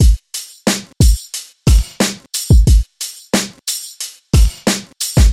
描述：BoomBap节拍编程为180bpm
Tag: 90 bpm Hip Hop Loops Drum Loops 918.89 KB wav Key : Unknown